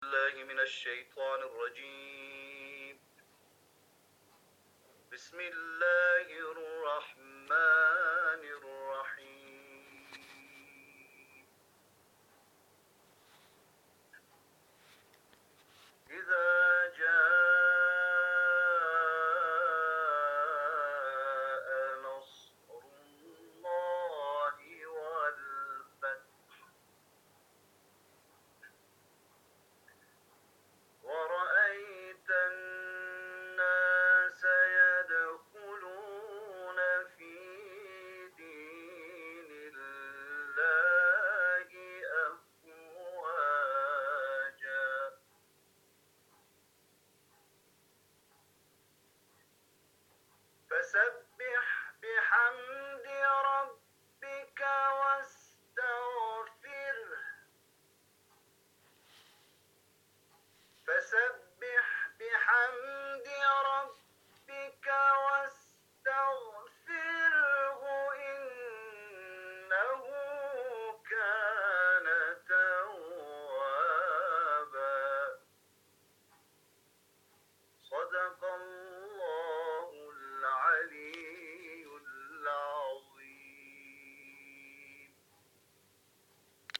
گزارشی از قرآنی‌ترین روز هفته در دانشگاه فرهنگیان + تلاوت
تلاوت سوره نصر